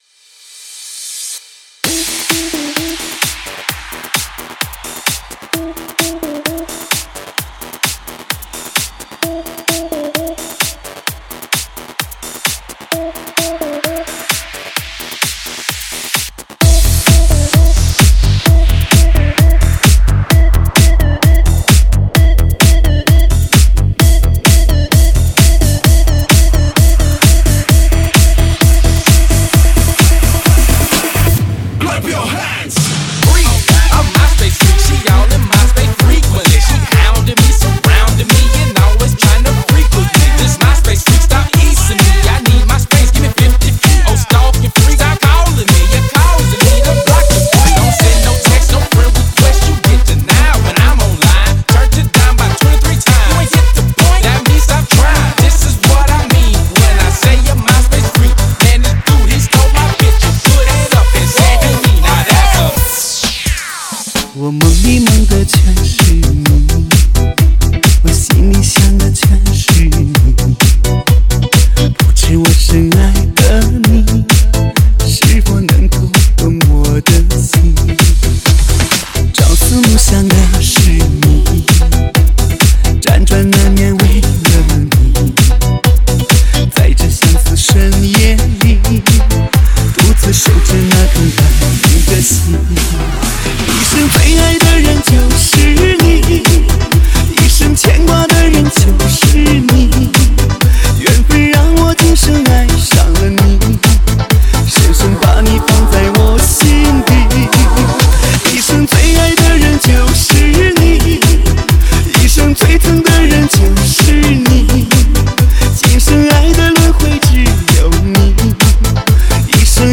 劲爆中文DJ
劲爆中文DJ，超级嗨潮DJ狂潮至尊，强势推出迪吧新舞曲。